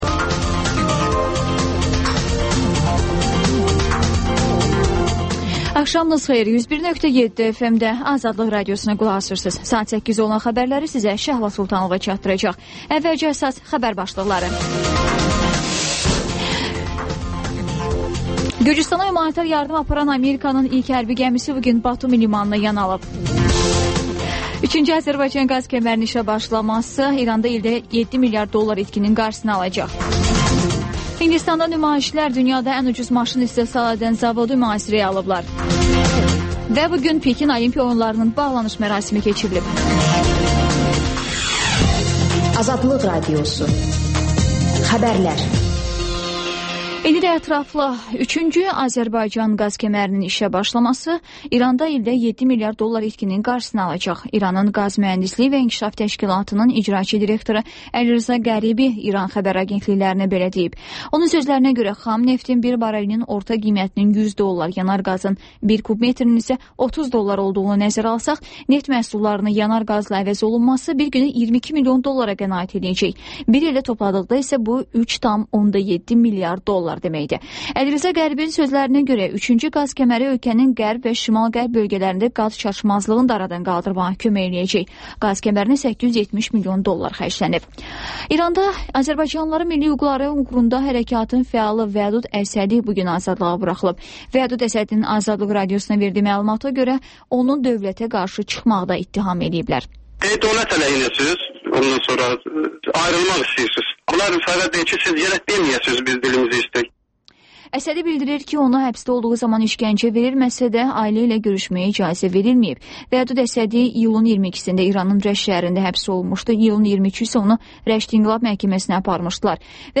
Xəbərlər, İZ: Mədəniyyət proqramı və TANINMIŞLAR rubrikası: Ölkənin tanınmış simaları ilə söhbət